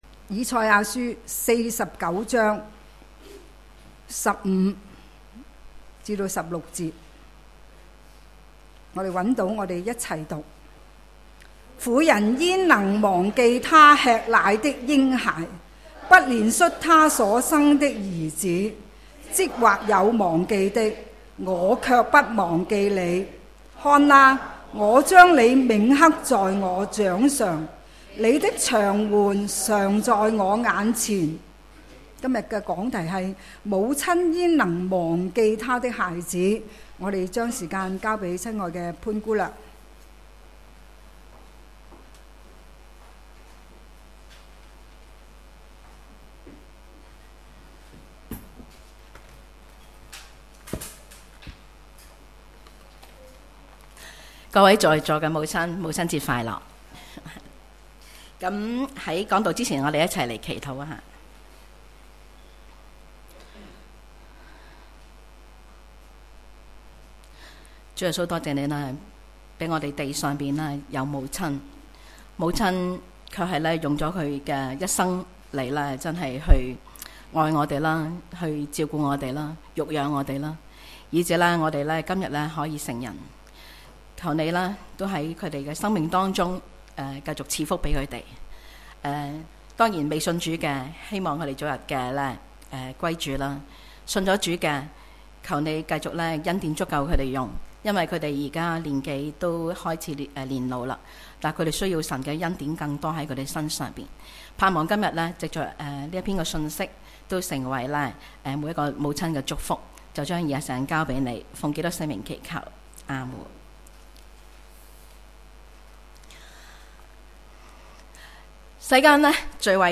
主日崇拜講道 – 母親焉能忘記她的孩子